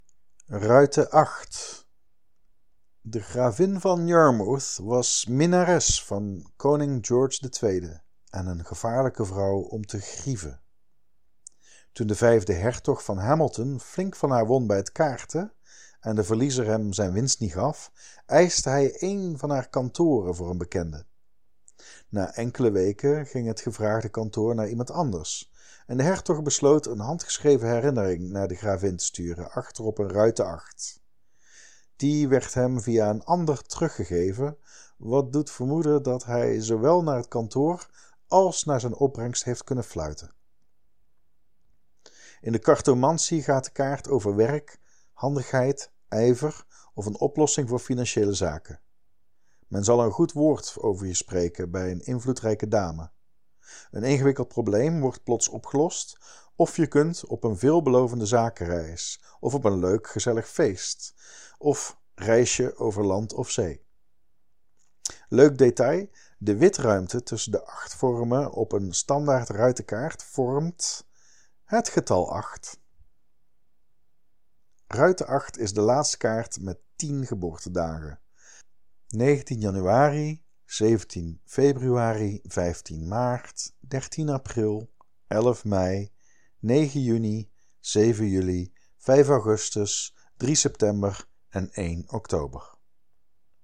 05.08b-Ruiten-acht-toelichting.mp3